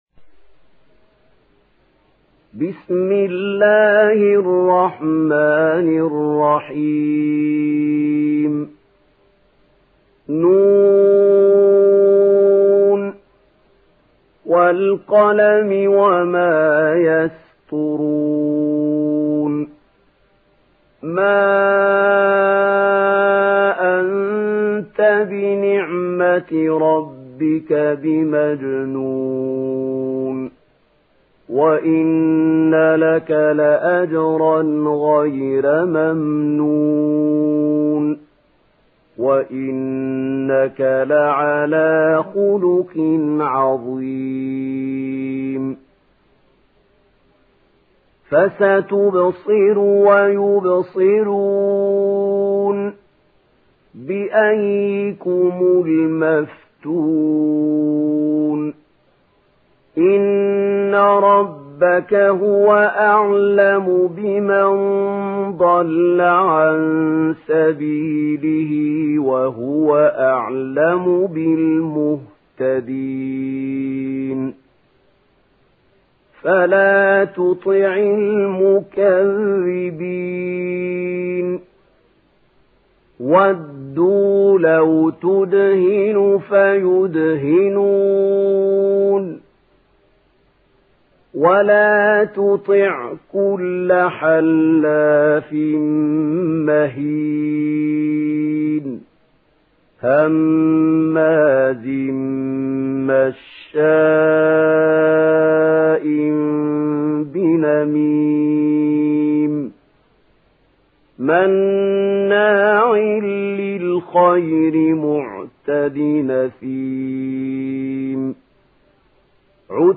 Surah Al-Qalam MP3 in the Voice of Mahmoud Khalil Al-Hussary in Warsh Narration
Surah Al-Qalam MP3 by Mahmoud Khalil Al-Hussary in Warsh An Nafi narration.
Murattal Warsh An Nafi